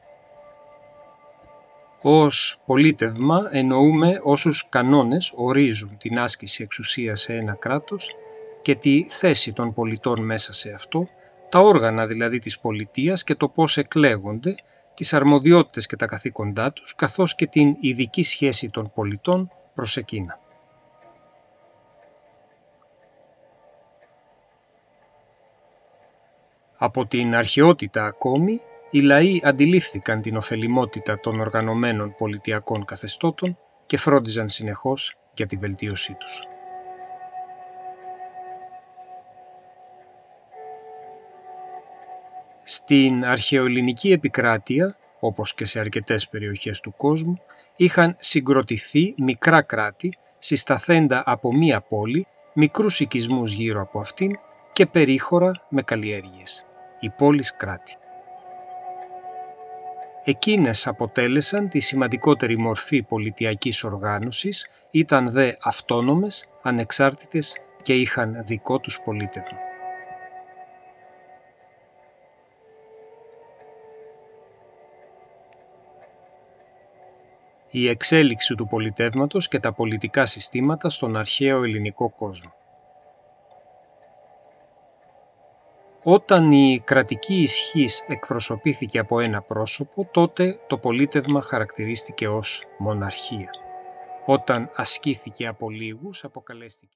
Το Audio Book περιλαμβάνει την αφήγηση από εμένα ενός μικρού χρονικού.